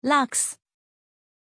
Aussprache von Lux
pronunciation-lux-zh.mp3